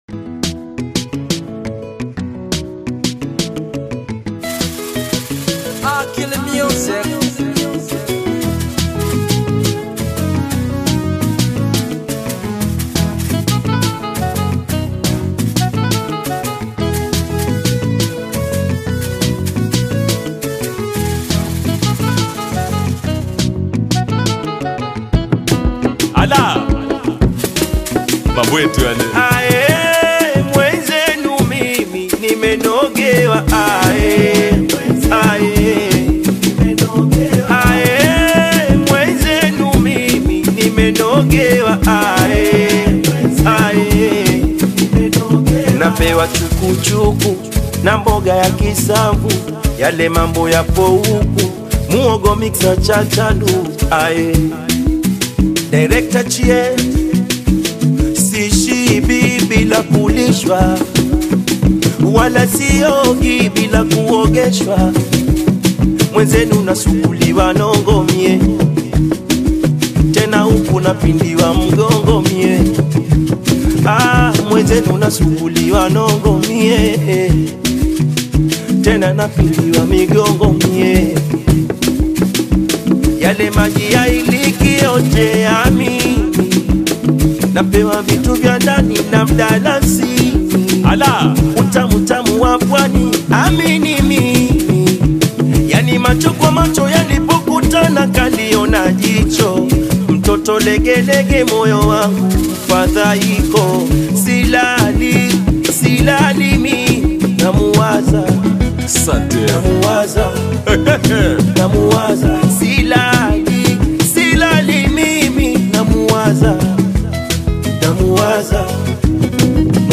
Home BAIKOKO TANGA AUDIO